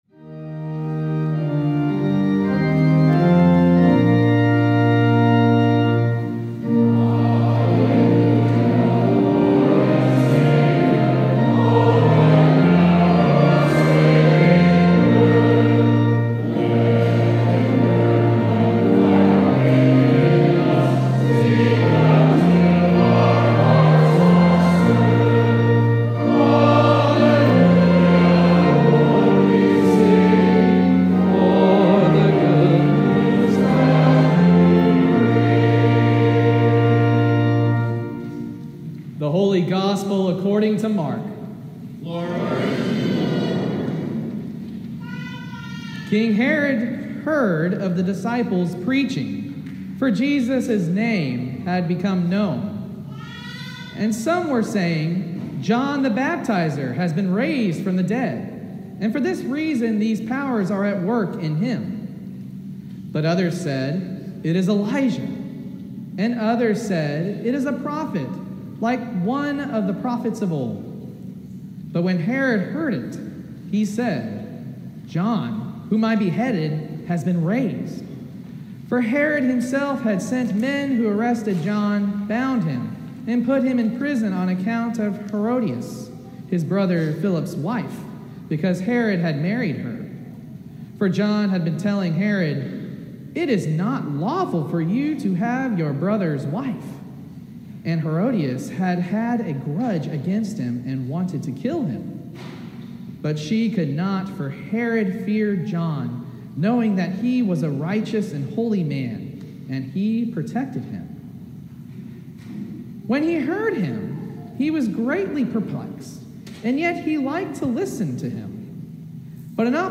Sermon from the Eighth Sunday After Pentecost